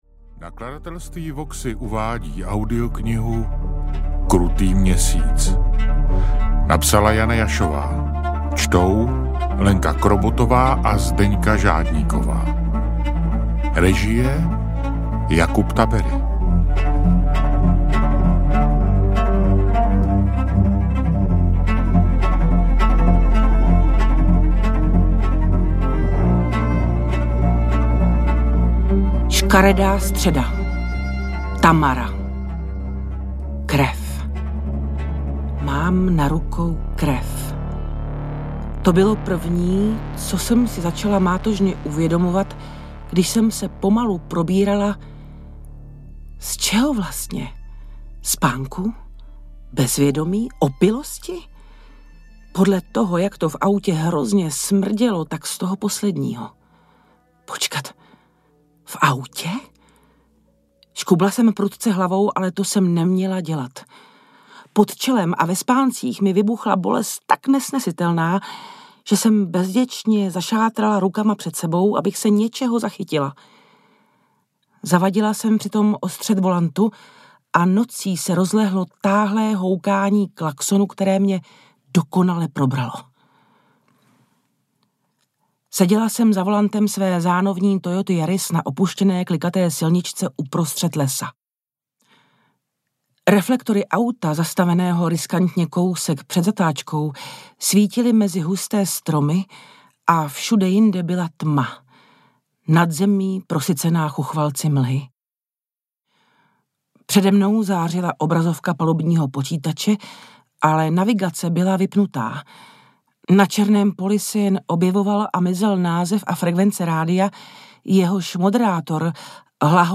Krutý měsíc audiokniha
Ukázka z knihy